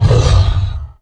MONSTER Growl Deep 08a Short (mono).wav